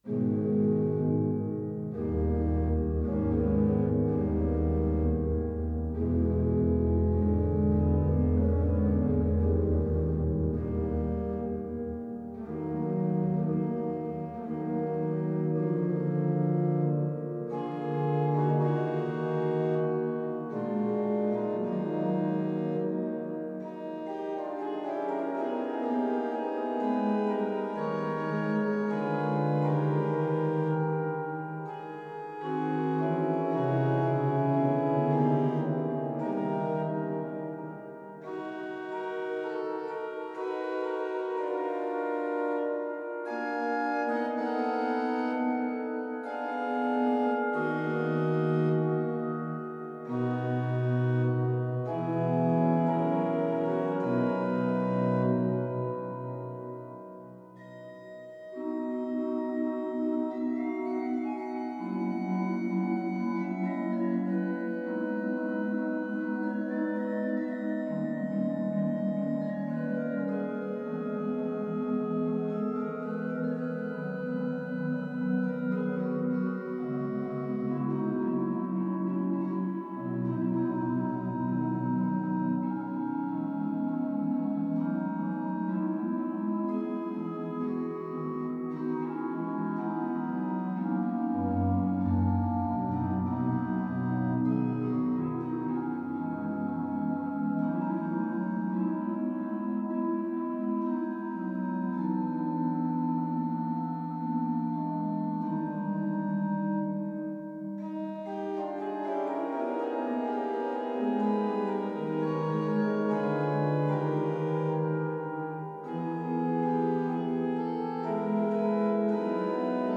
• le silence au tombeau
• le tremblement de la terre
• le chant triomphal de l’ange